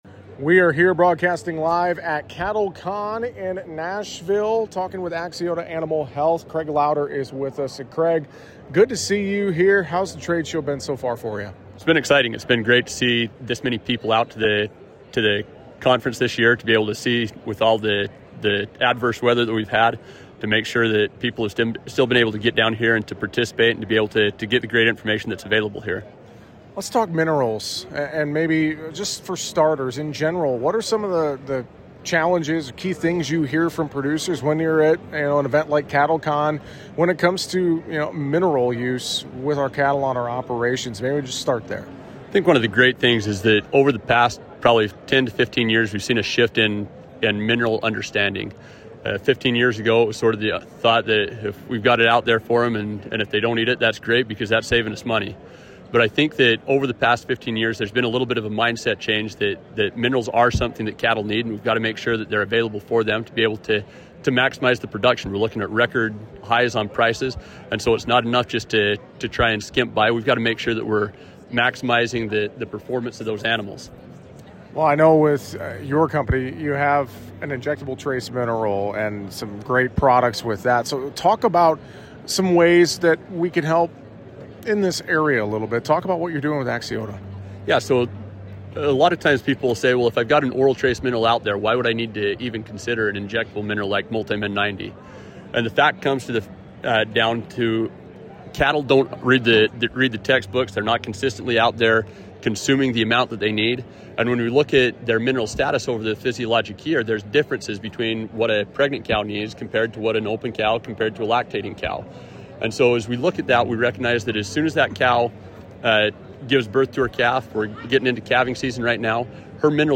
(NASHVILLE, TN) — During CattleCon 2026, we had a conversation with Axiota Animal Health to learn more about the company and how they help beef and dairy cattle stay healthy, productive, and resilient.